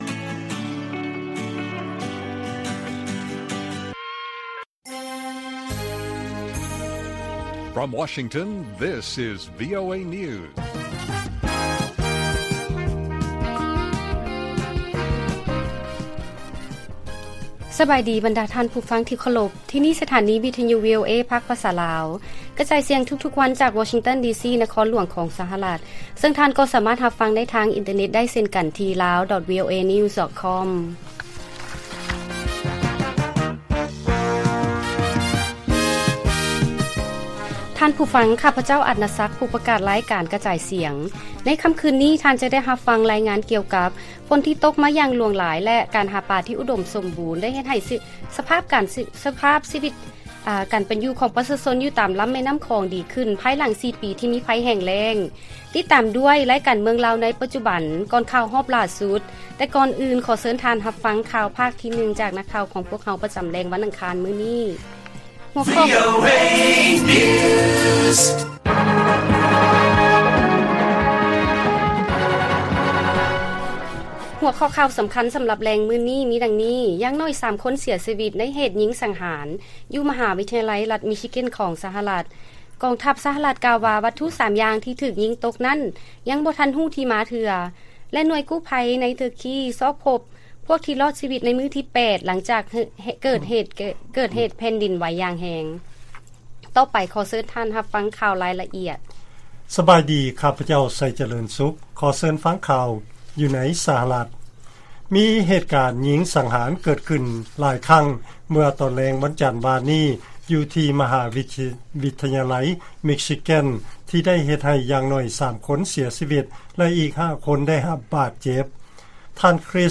ລາຍການກະຈາຍສຽງຂອງວີໂອເອ ລາວ: ຢ່າງໜ້ອຍ 3 ຄົນເສຍຊີວິດ ໃນເຫດຍິງສັງຫານ ຢູ່ມະຫາວິທະຍາໄລ ລັດມິຊິແກນ ຂອງສະຫະລັດ